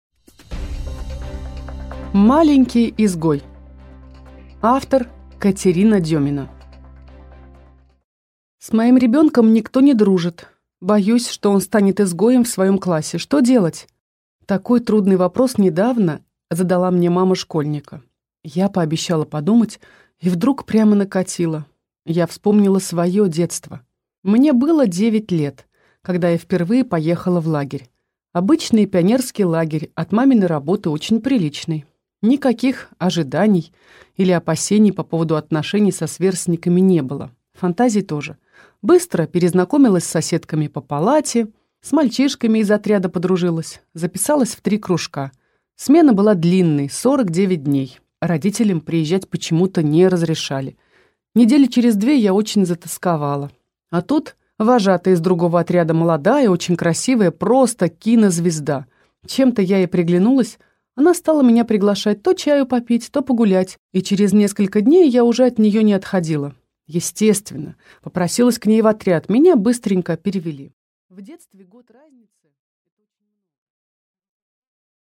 Аудиокнига Маленький изгой | Библиотека аудиокниг
Прослушать и бесплатно скачать фрагмент аудиокниги